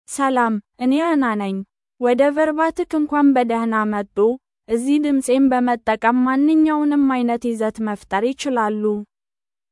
FemaleAmharic (Ethiopia)
AnnaFemale Amharic AI voice
Anna is a female AI voice for Amharic (Ethiopia).
Voice sample
Female
Anna delivers clear pronunciation with authentic Ethiopia Amharic intonation, making your content sound professionally produced.